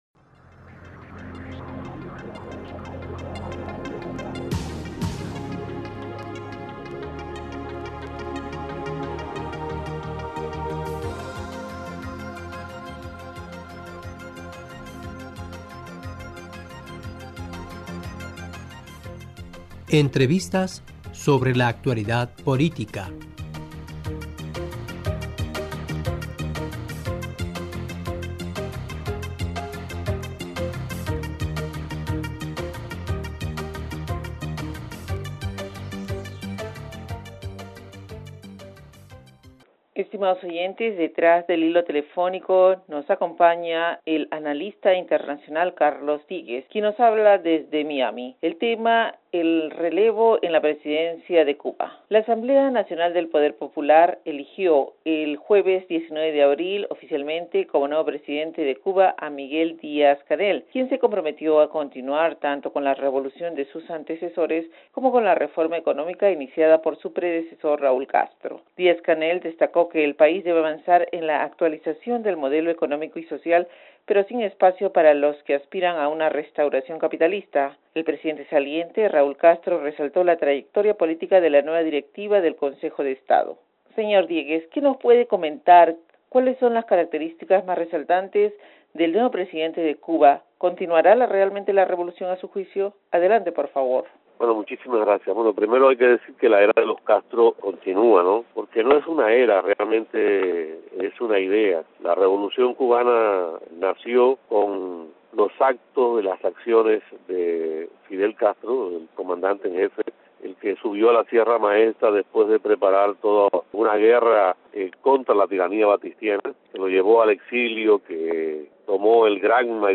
detrás del hilo telefónico